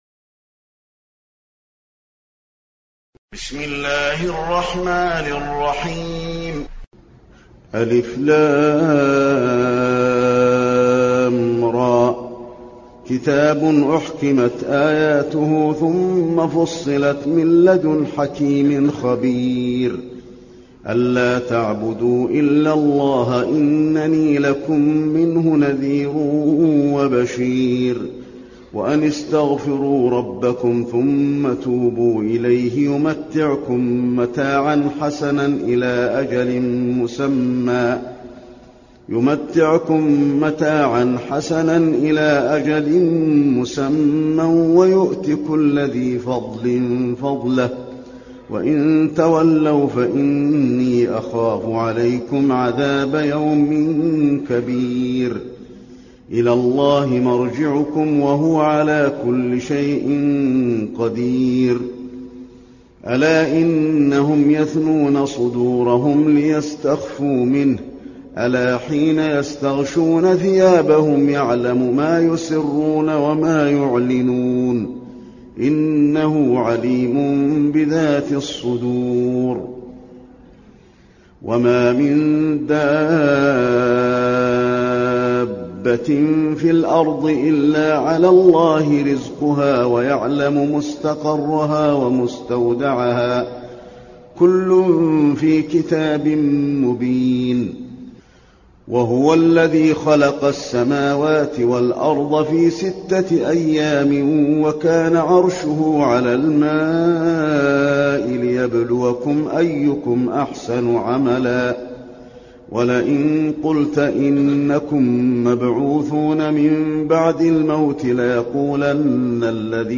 المكان: المسجد النبوي هود